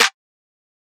MZ Snareclap [Metro #8].wav